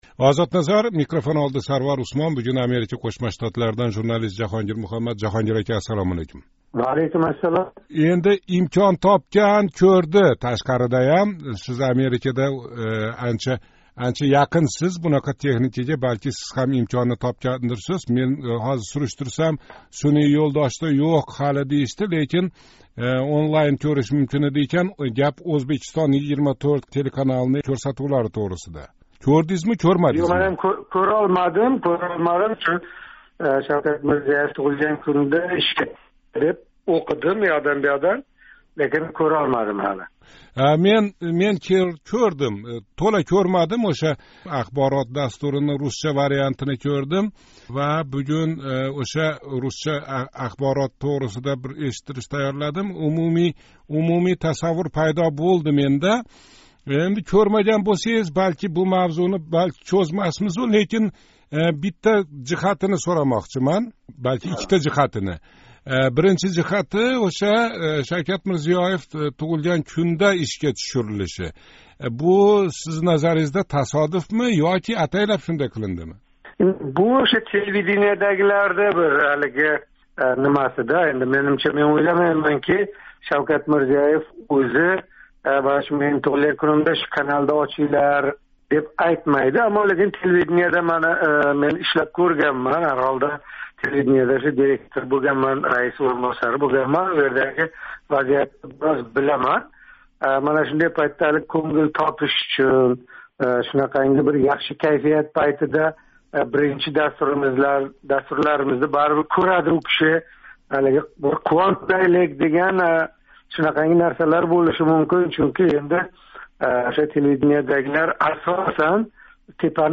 Савол жавоби устида ўйлар экансиз, суҳбатни тингланг. Суҳбатки, янги телеканал ҳақида, йилига тўрттадан китоб ёзадиган, иккитадан шогирд чиқарадиган ёзувчи-шоирлар ҳақида ва умуман, ҳар доимгидай, куннинг гаплари атрофида.